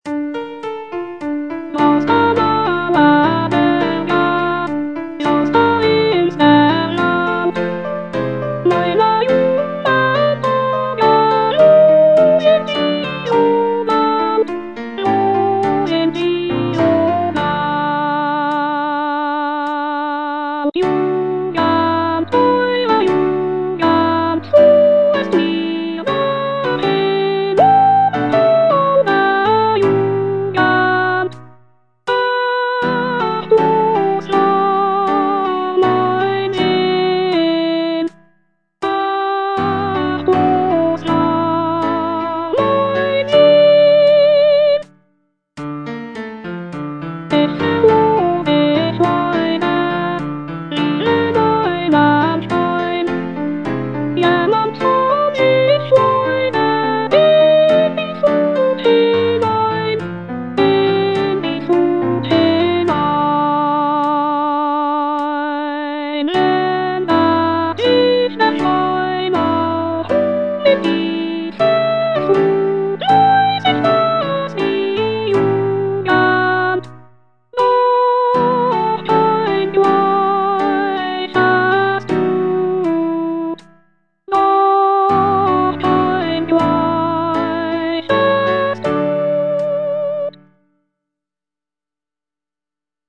J. BRAHMS - VERLORENE JUGEND Soprano (Voice with metronome) Ads stop: auto-stop
Brahms' music in this piece is characterized by expressive melodies, rich harmonies, and poignant emotions, effectively capturing the essence of youthful innocence and the bittersweet feelings associated with its passing.